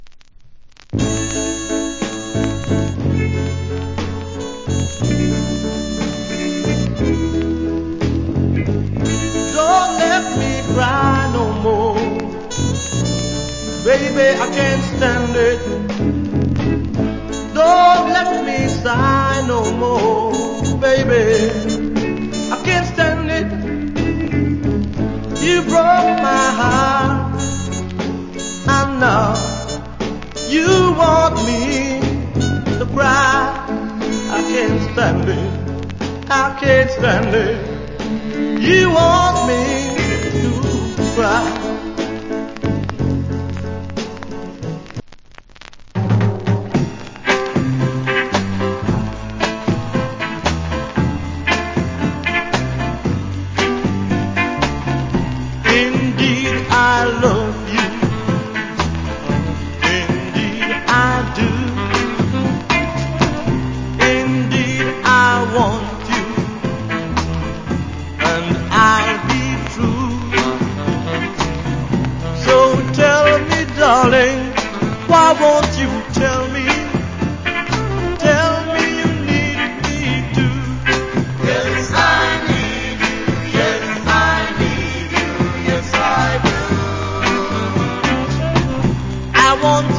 Nice Ballad Vocal.